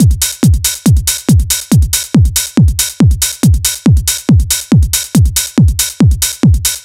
NRG 4 On The Floor 021.wav